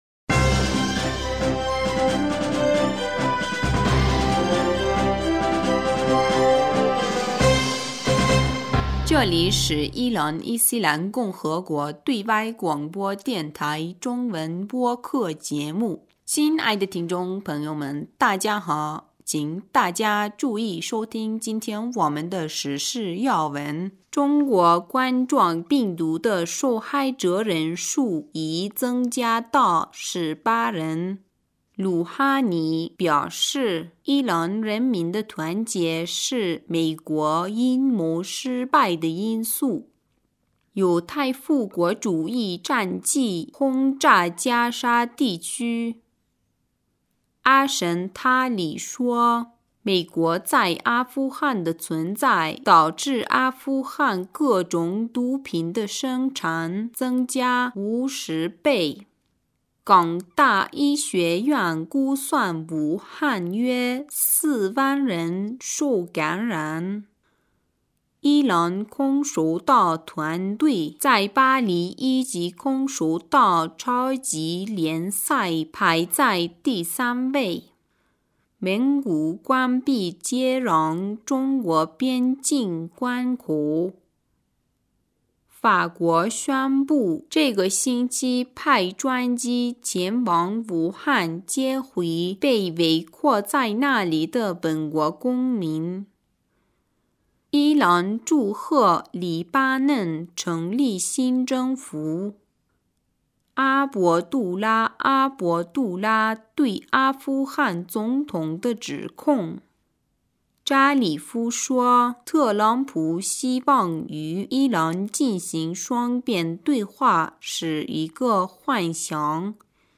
2020年1月27日 新闻